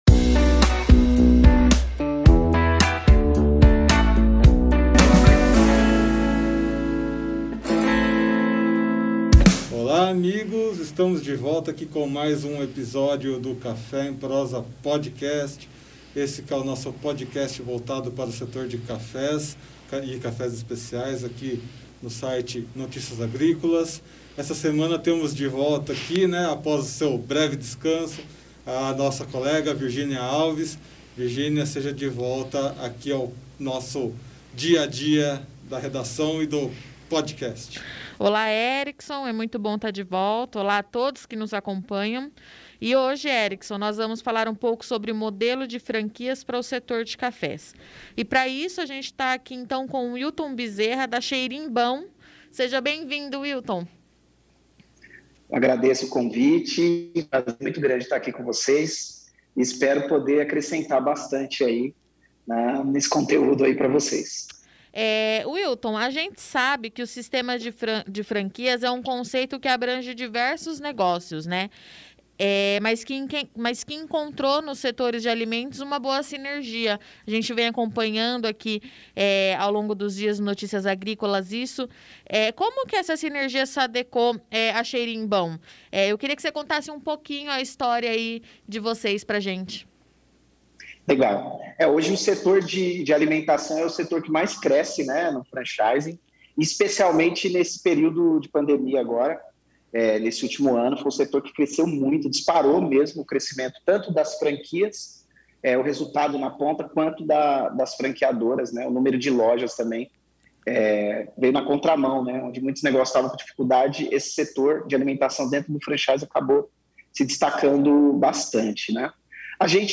Em conversas descontraídas, essas pessoas contarão suas histórias e trarão suas ligações com essa bebida que é uma das mais apreciadas no mundo todo.